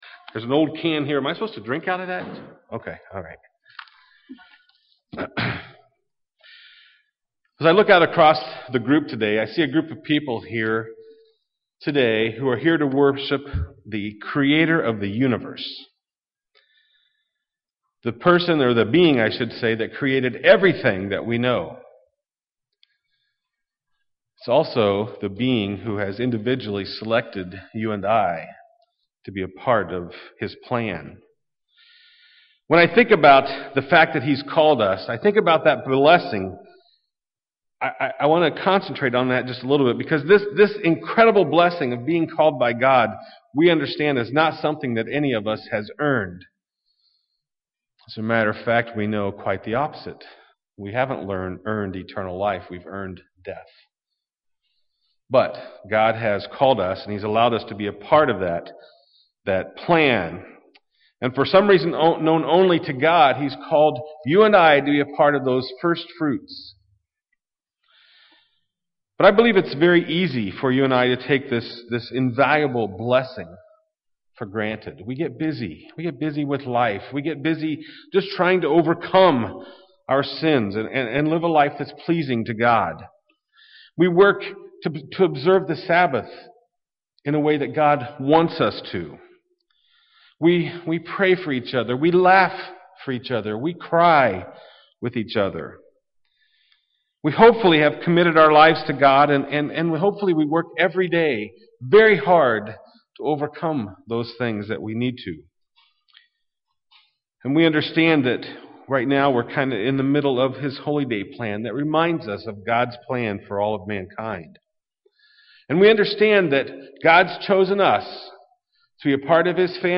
This sermon was given at the Jamaica 2013 Feast site.